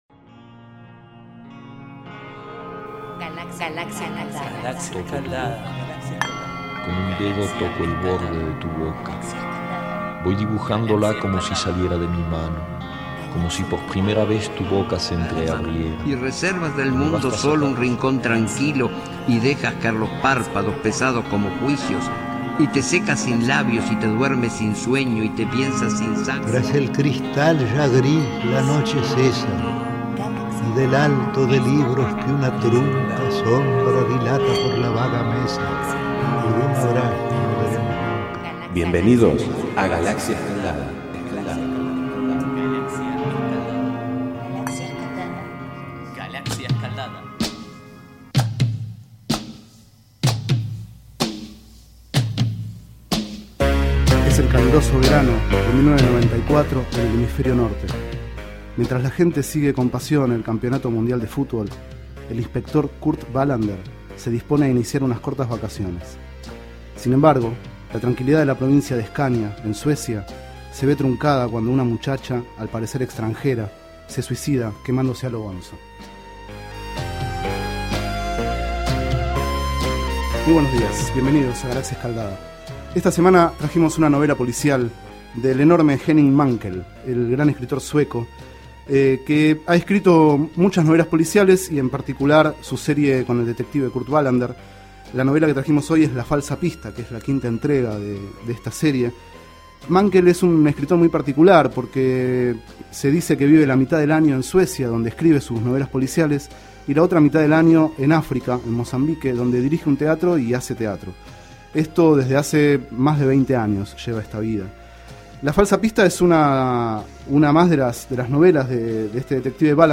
Este es el 38º micro radial, emitido en los programas Enredados, de la Red de Cultura de Boedo, y En Ayunas, el mañanero de Boedo, por FMBoedo, realizado el 03 de diciembre de 2011, sobre el libro La falsa pista, de Henning Mankell.